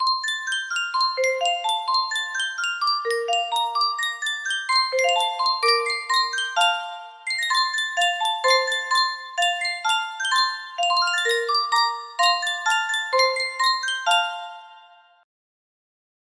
Sankyo Music Box - Jingle Bells J music box melody
Full range 60